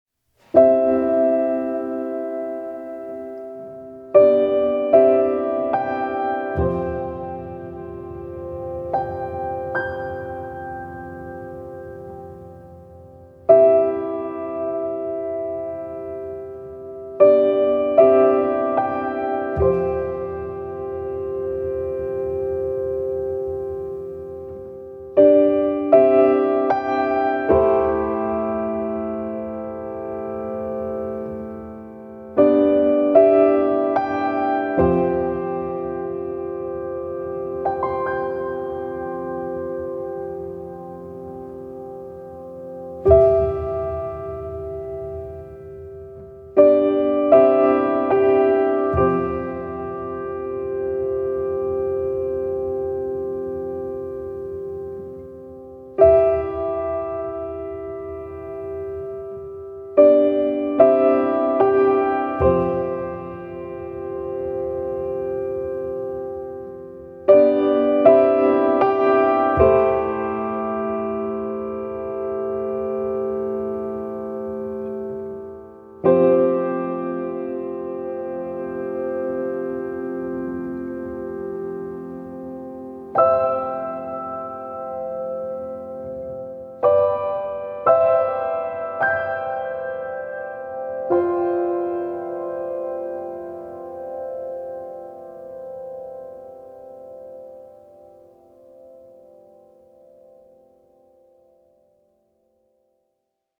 سبک پیانو , مدرن کلاسیک , موسیقی بی کلام
تکنوازی پیانو